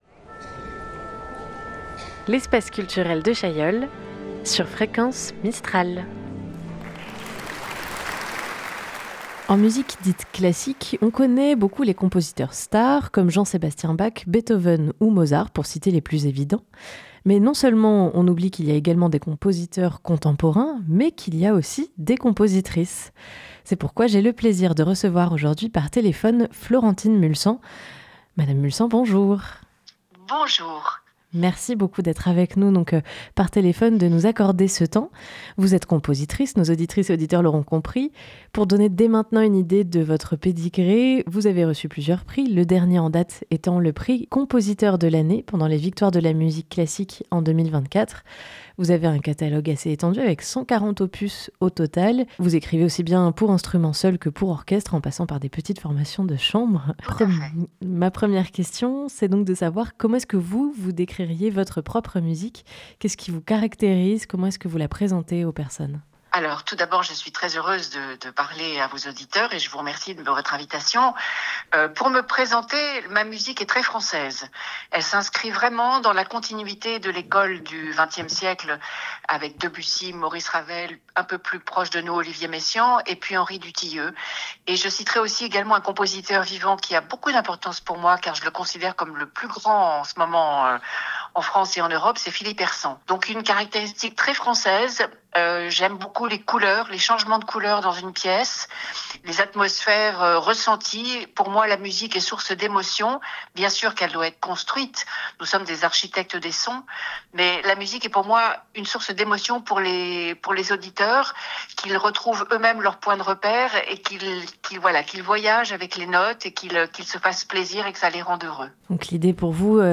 Compositrice de l'année pendant les Victoires de la Musique Classique en 2024, autrice de 140 opus musicaux, elle nous a accordé un entretien téléphonique pour nous parler de son travail. Ensemble nous avons échangé autour de son rapport aux instruments et aux musiciens, de sa sensibilité, de son rapport au public... et bien sûr de ses souvenirs d'enfance ! 260420 - itw Florentine Mulsant.mp3 (52.65 Mo)